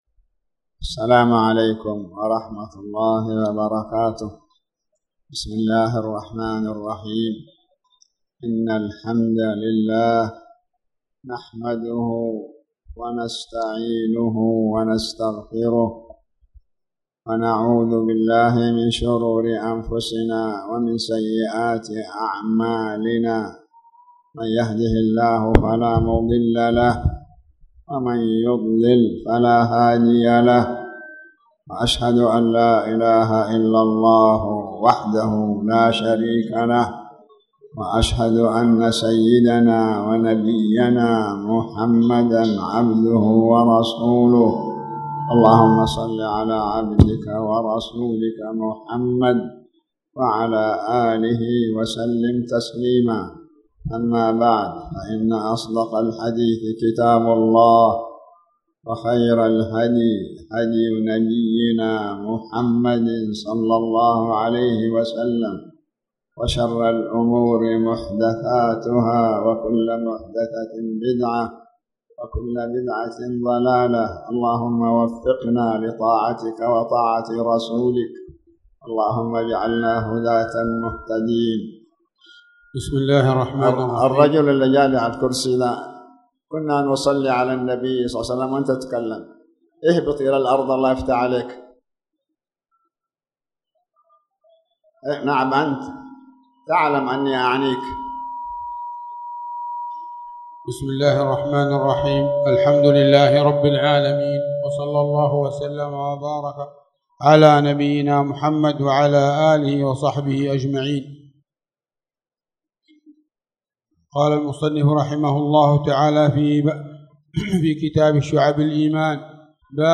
تاريخ النشر ٢٦ ربيع الثاني ١٤٣٨ هـ المكان: المسجد الحرام الشيخ